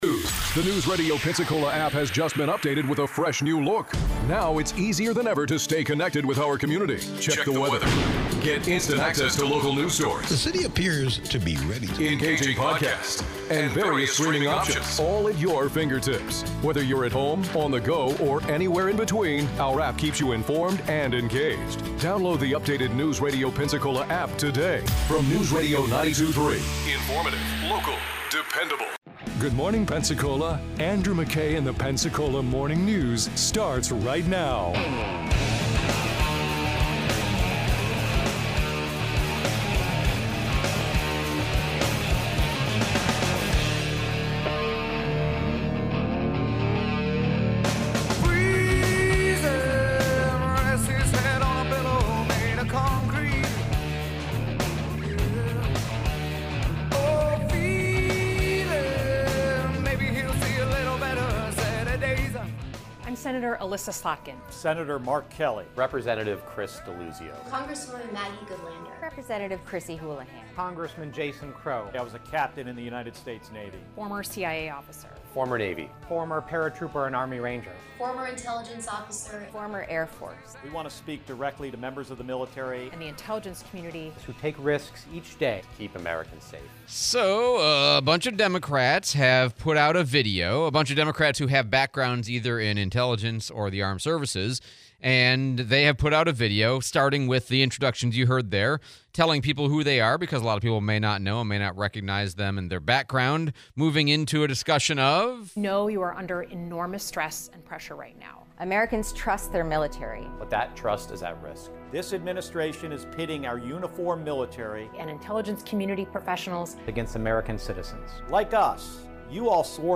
Dems create video telling military to follow rules, interview